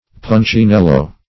Meaning of punchinello. punchinello synonyms, pronunciation, spelling and more from Free Dictionary.
Punchinello \Pun`chi*nel"lo\, n.